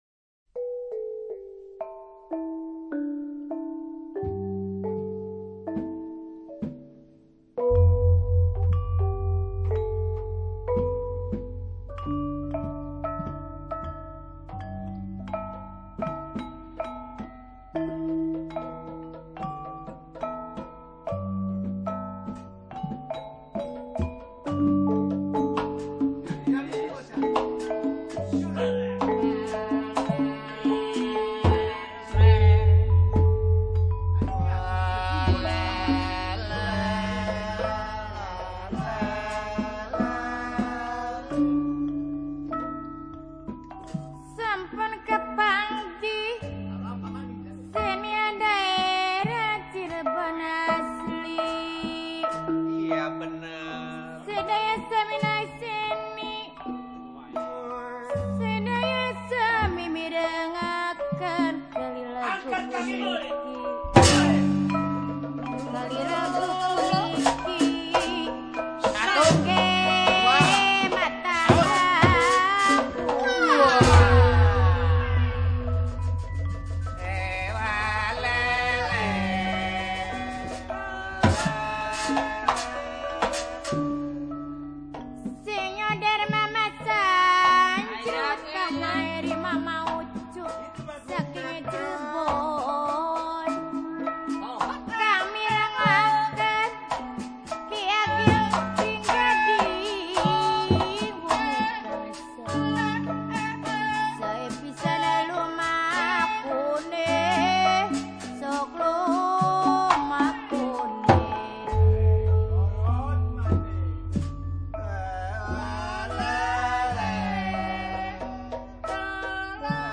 井里汶甘美朗 甘美朗音乐的另一张面具 井里汶民俗音乐首度公开 井里汶(Cirebon